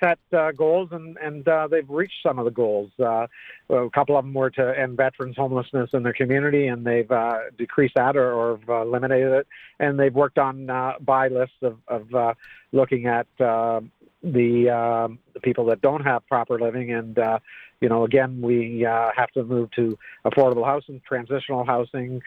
Mayor Neil Ellis tells Quinte news this motion goes back to Hastings County and will come back to the Homelessness session to be considered once again.